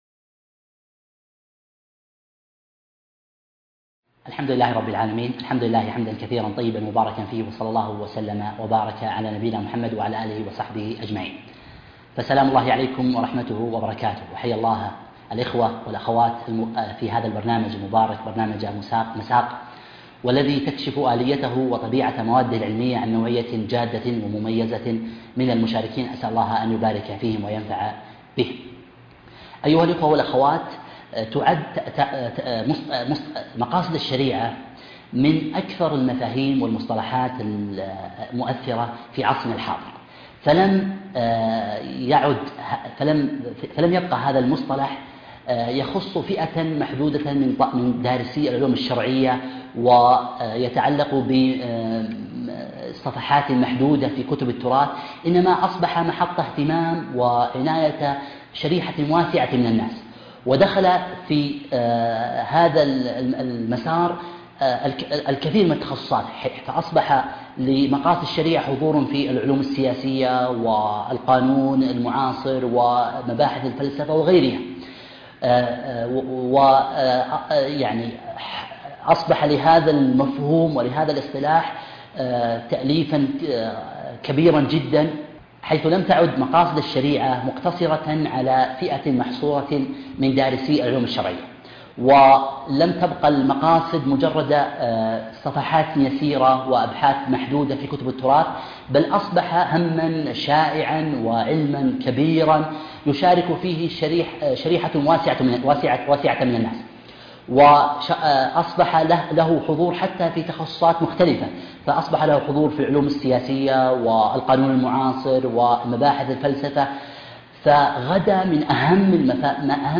محاضرة علمية مقاصد الشريعة